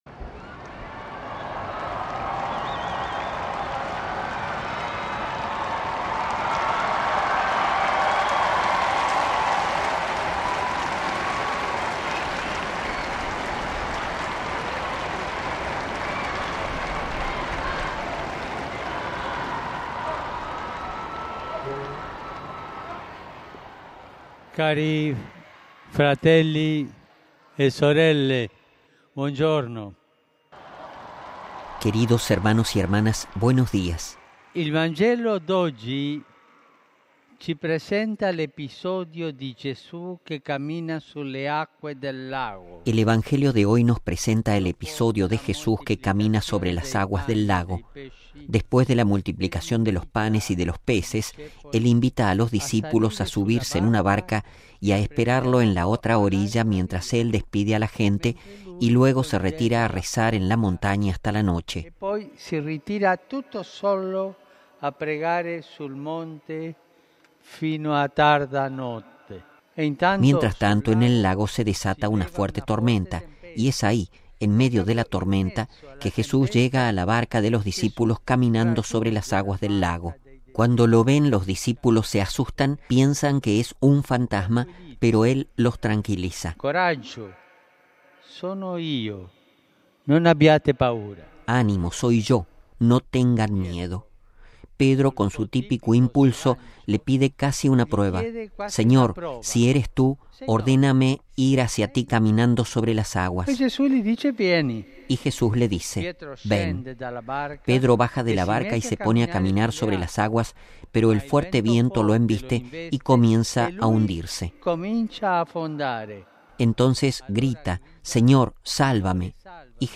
Texto completo de la reflexión del Papa antes del rezo a la Madre de Dios: RealAudio